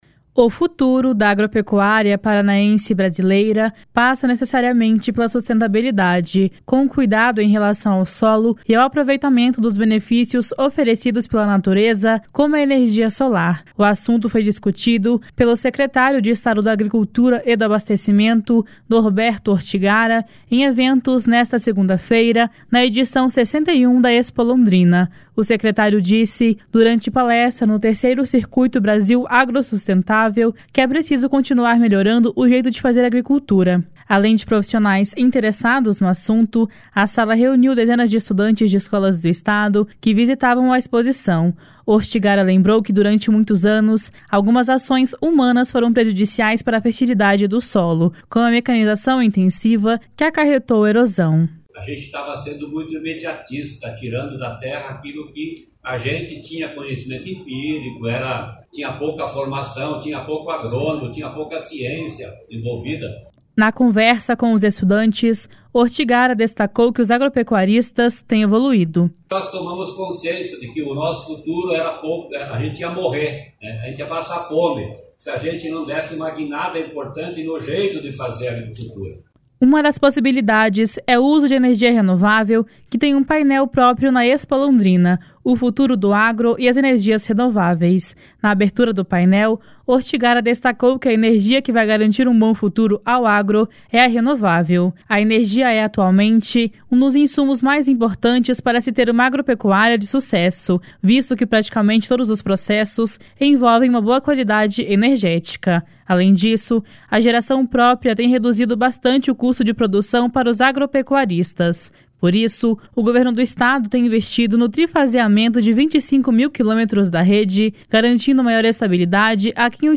O futuro da agropecuária paranaense e brasileira passa necessariamente pela sustentabilidade, com o cuidado em relação ao solo e ao aproveitamento dos benefícios oferecidos pela natureza, como a energia solar. O assunto foi discutido pelo secretário de Estado da Agricultura e do Abastecimento, Norberto Ortigara, em eventos nesta segunda-feira na edição 61 da ExpoLondrina. O secretário disse durante palestra no III Circuito Brasil Agrossustentável que é preciso continuar melhorando o jeito de fazer agricultura.